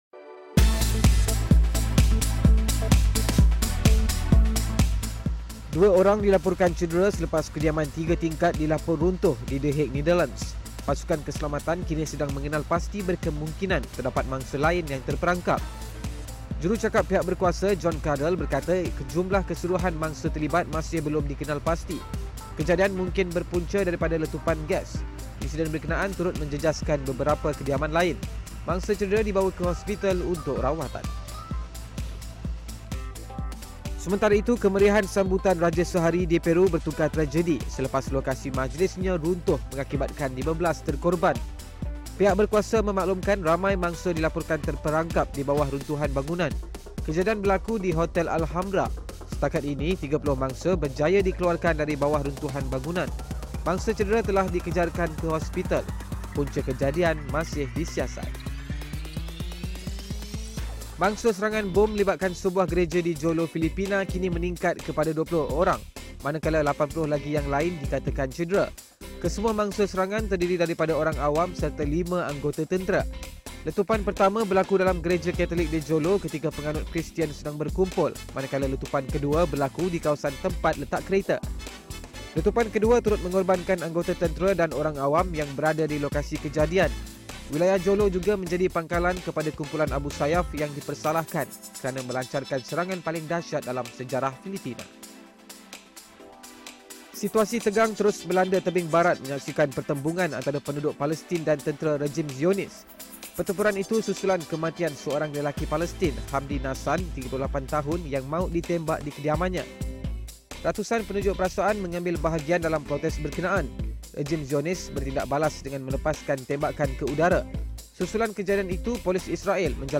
Ikuti rangkuman berita utama yang menjadi tumpuan sepanjang hari di Astro AWANI menerusi AWANI Ringkas :